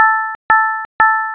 chat_notify_triple.wav